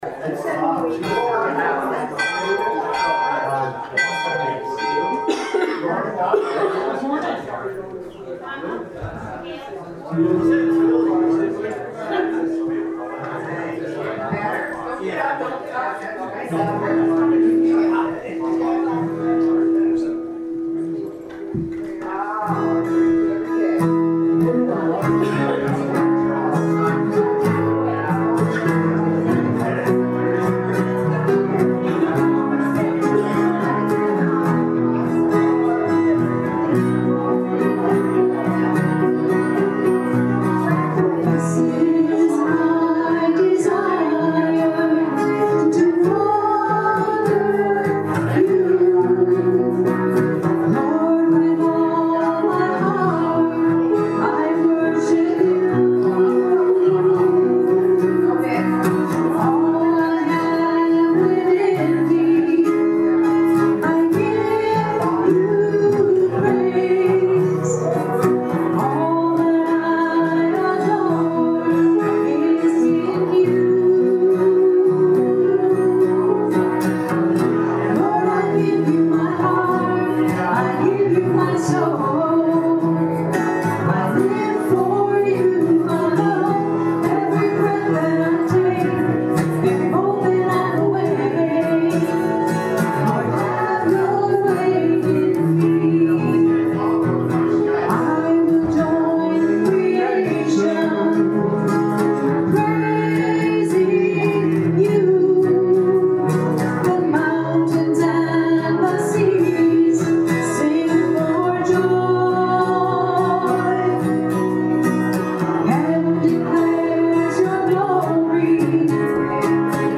Prelude: This is My Desire
Hymn of Joy: #384 This is My Father’s World
Benediction & Choral Amen